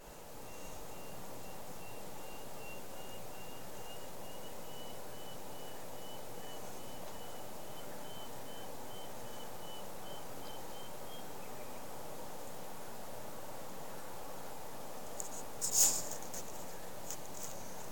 nachtgeräusche